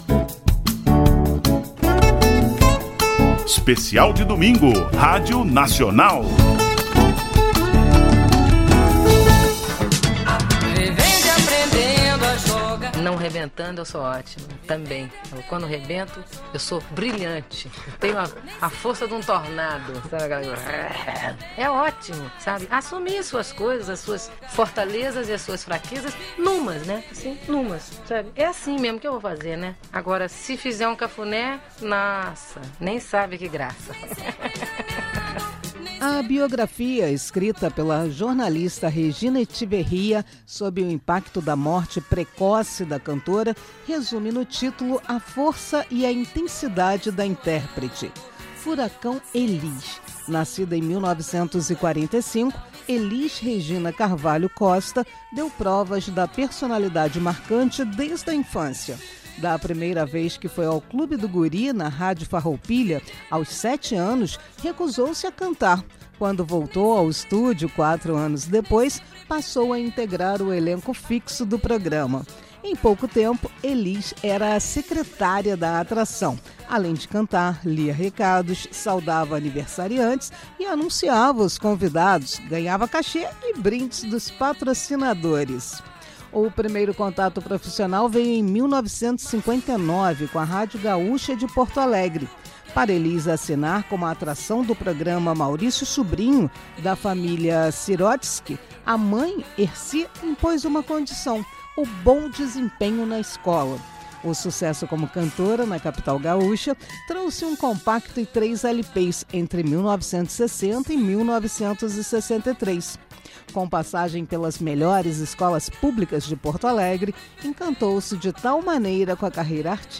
Programa especial da EBC fala da trajetória da cantora e traz depoimentos da própria artista, considerada um dos nomes mais importantes da MPB.
Para marcar a data, a Empresa Brasil de Comunicação – EBC produziu um programa especial, no ar pela Rádio Nacional, e que além de passear pela história da Pimentinha (seu apelido), reúne depoimentos da própria artista, retirados de áudios conservados pelo Acervo das Rádios estatais.